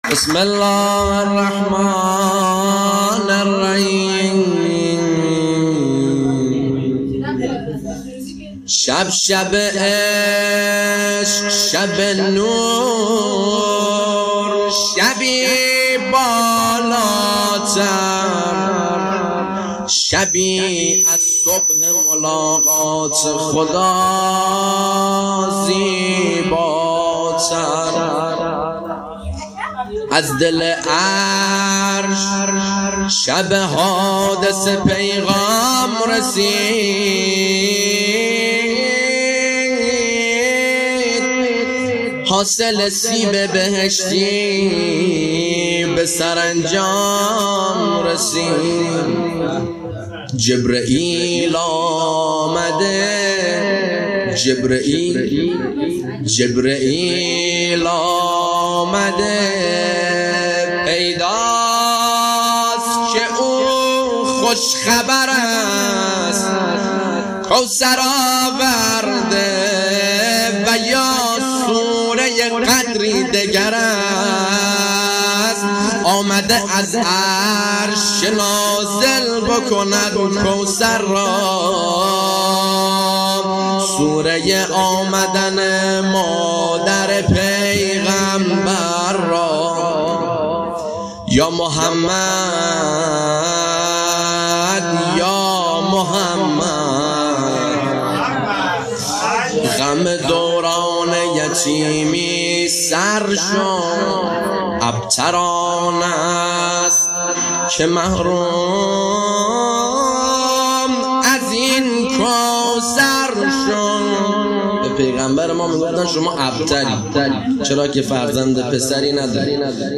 مدح خوانی
جشن ها